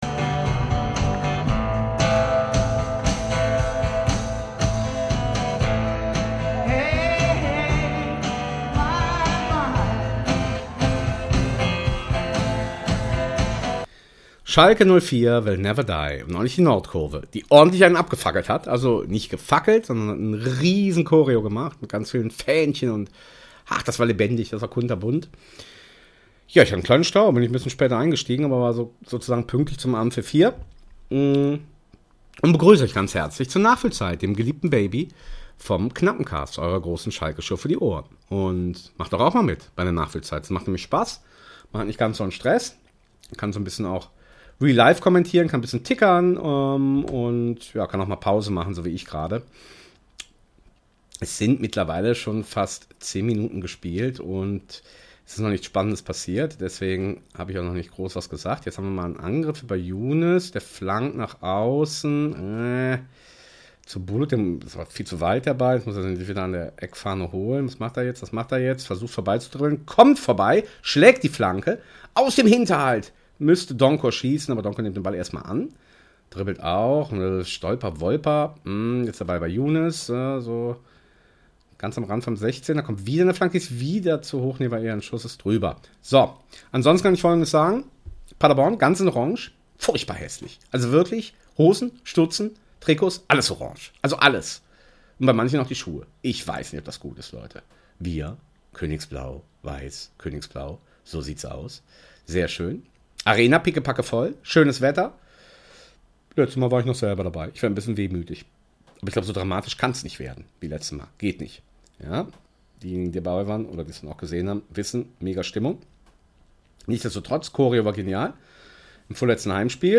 Schalke Audio Re-Live: Schalke 04 - SC Paderborn 07